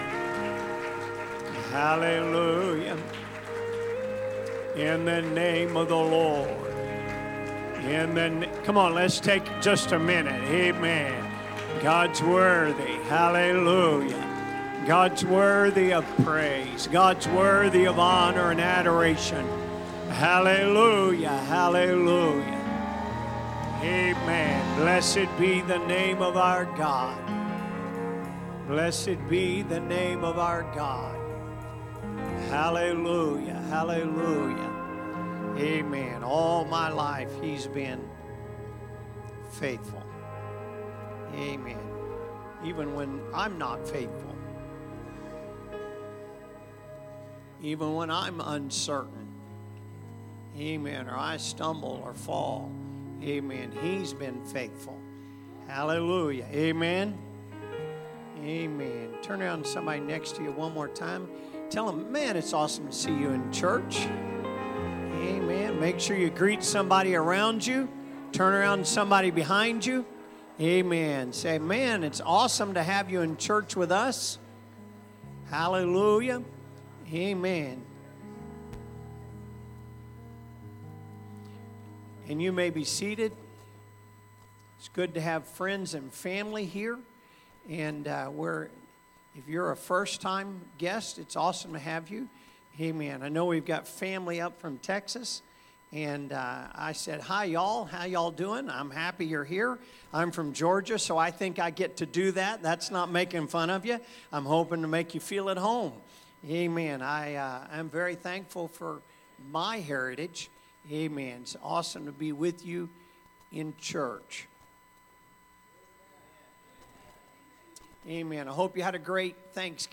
Sermons | Elkhart Life Church
Sunday Service - Part 22